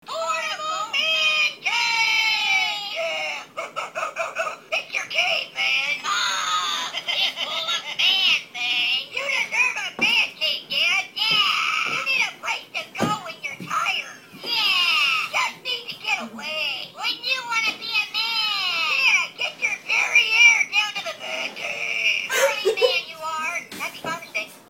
Portable Man Cave! is a hoops&yoyo greeting card with sound made for Father's Day.
Card sound